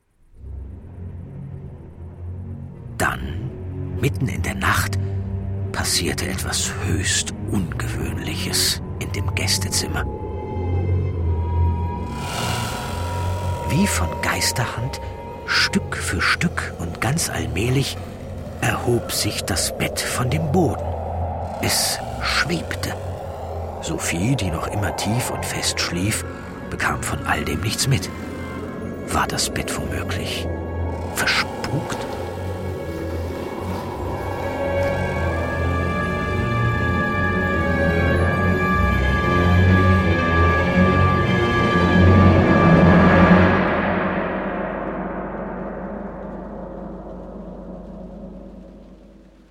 Ravensburger HUI BUH Neue Welt - Entführung in die Geisterwelt ✔ tiptoi® Hörbuch ab 4 Jahren ✔ Jetzt online herunterladen!